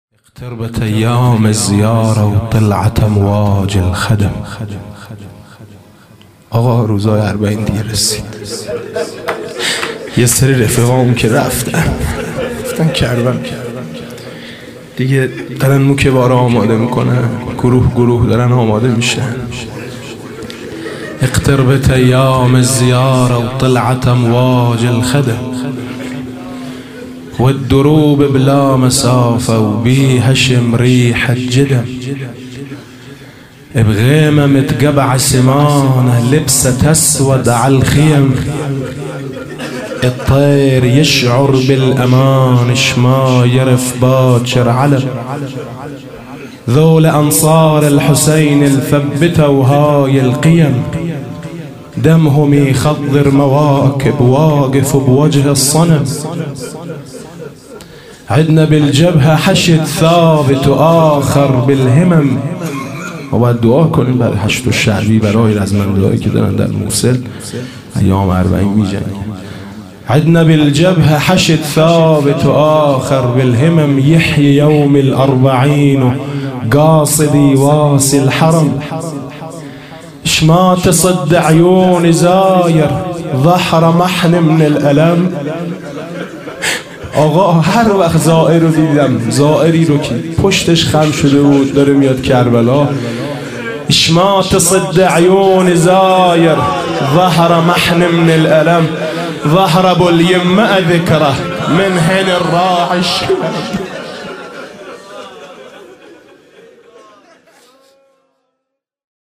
دکلمه - یحیی یوم الاربعین